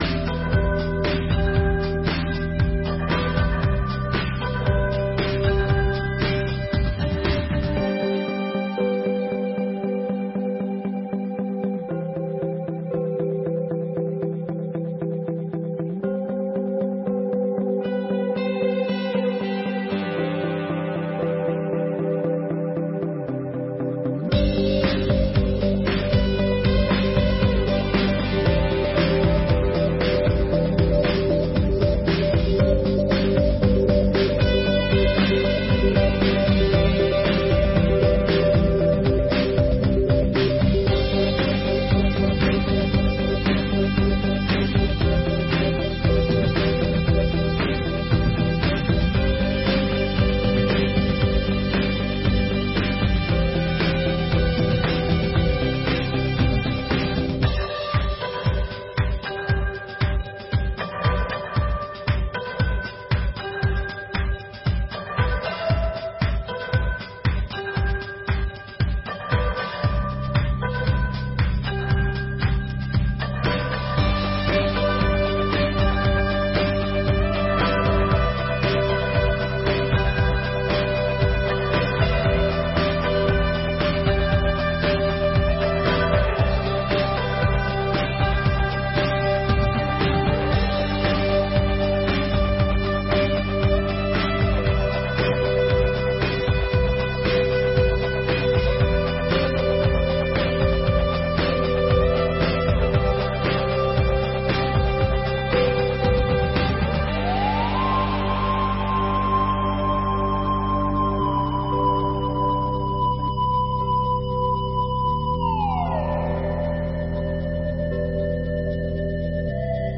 26ª Sessão Ordinária de 2024